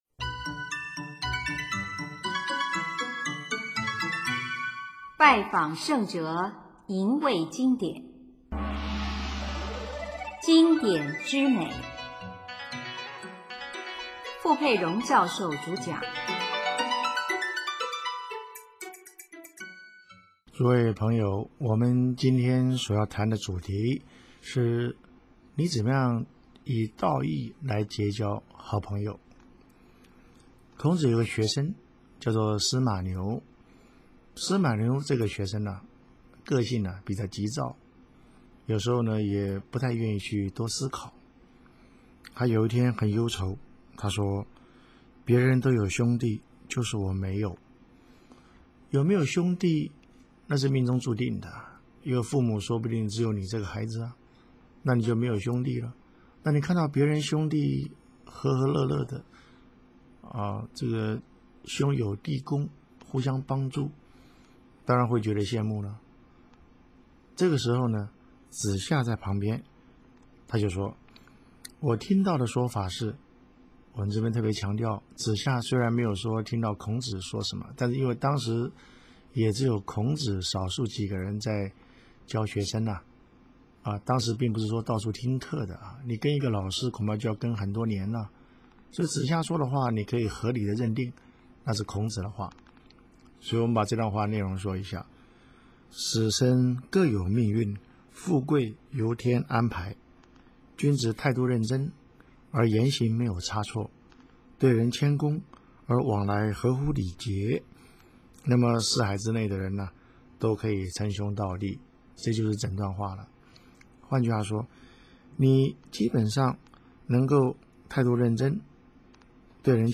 主讲：傅佩荣教授